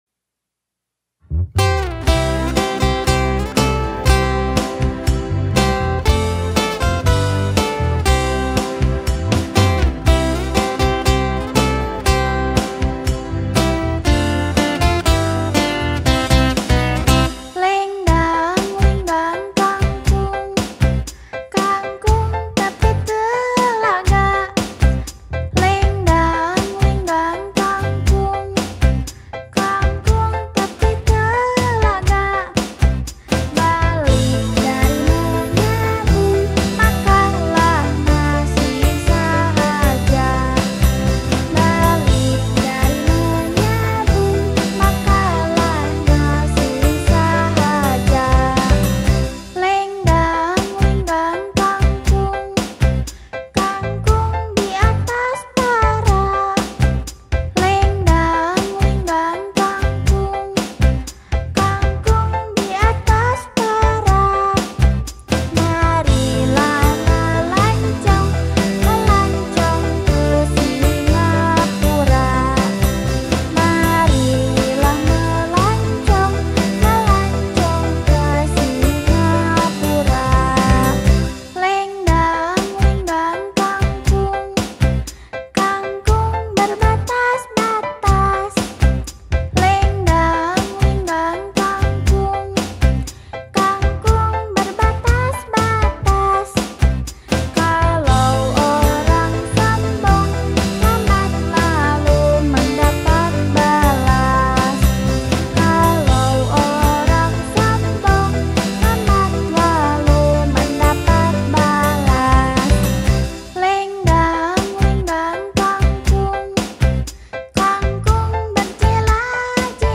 Rearranged in 2 parts harmony By
Lagu Kanak-kanak